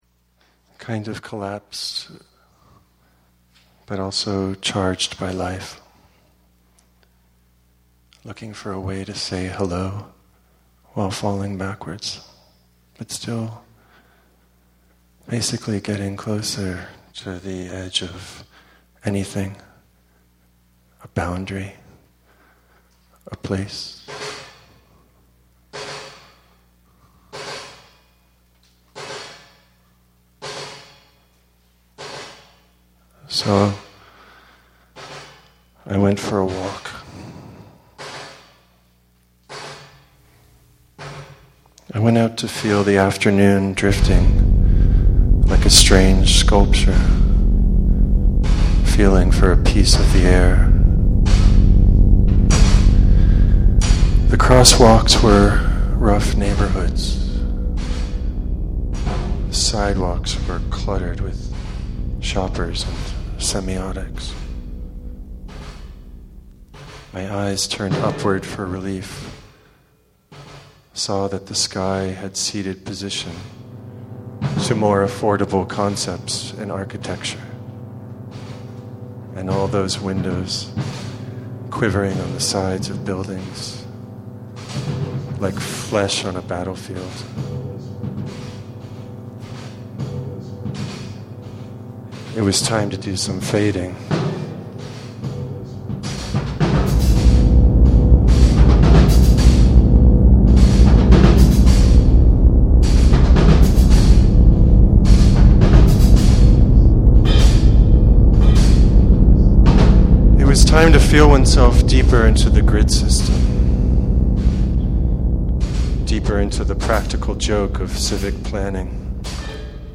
Performance Excerpt